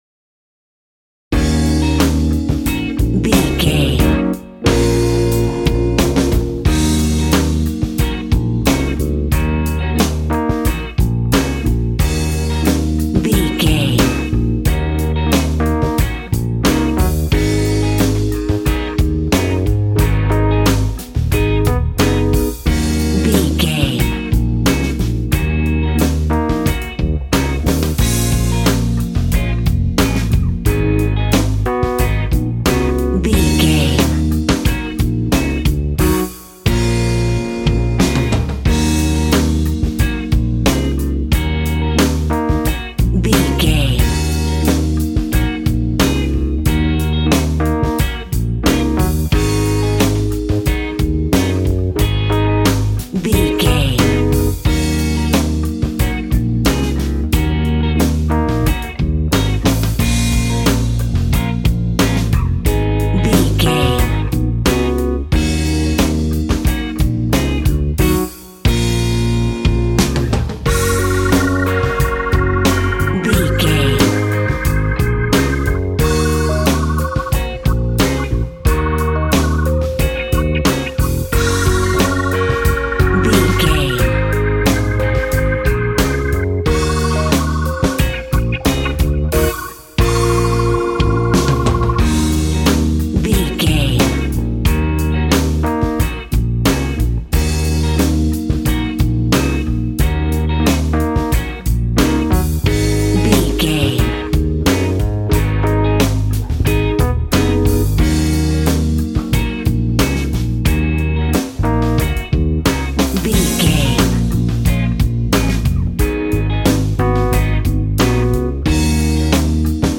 Aeolian/Minor
E♭
sad
mournful
bass guitar
electric guitar
electric organ
drums